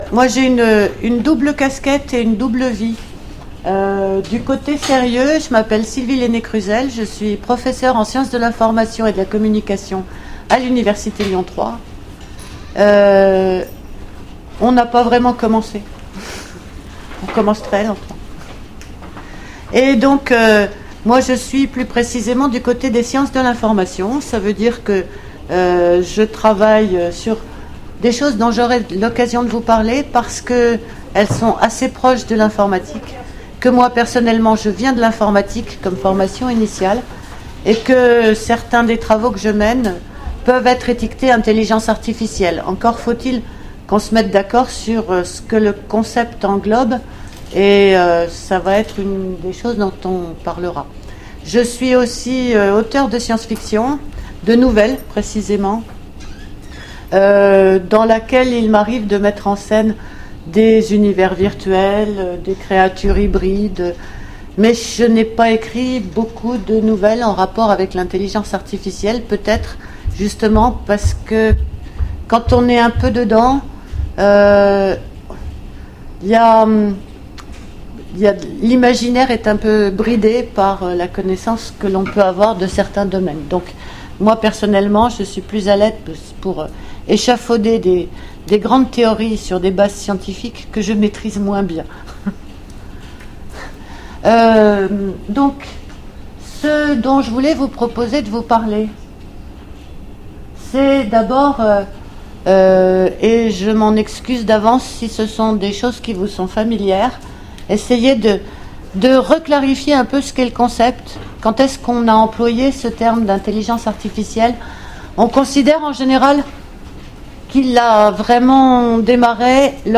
Mots-clés Intelligence artificielle Conférence Partager cet article